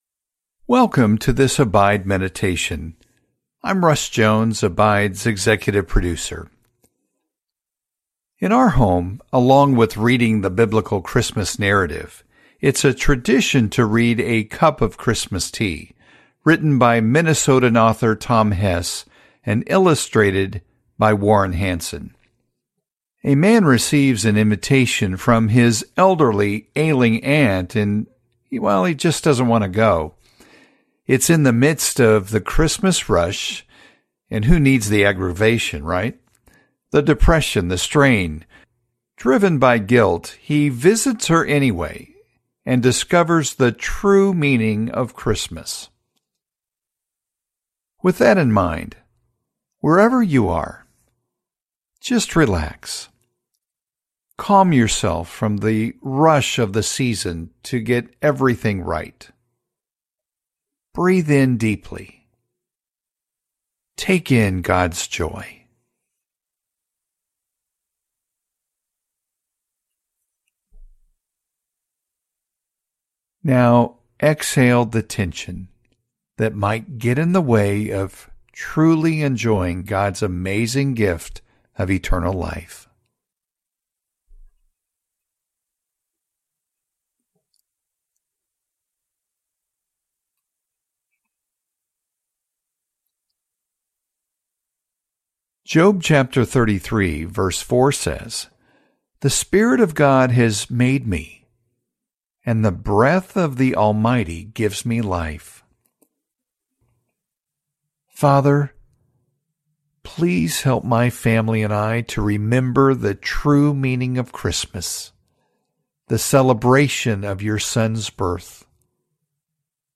Join Abide for a “Cup of Christmas Tea” as we hear the story of a young man's reluctant visit to an elderly aunt at Christmastime, and the unexpected joy it brings.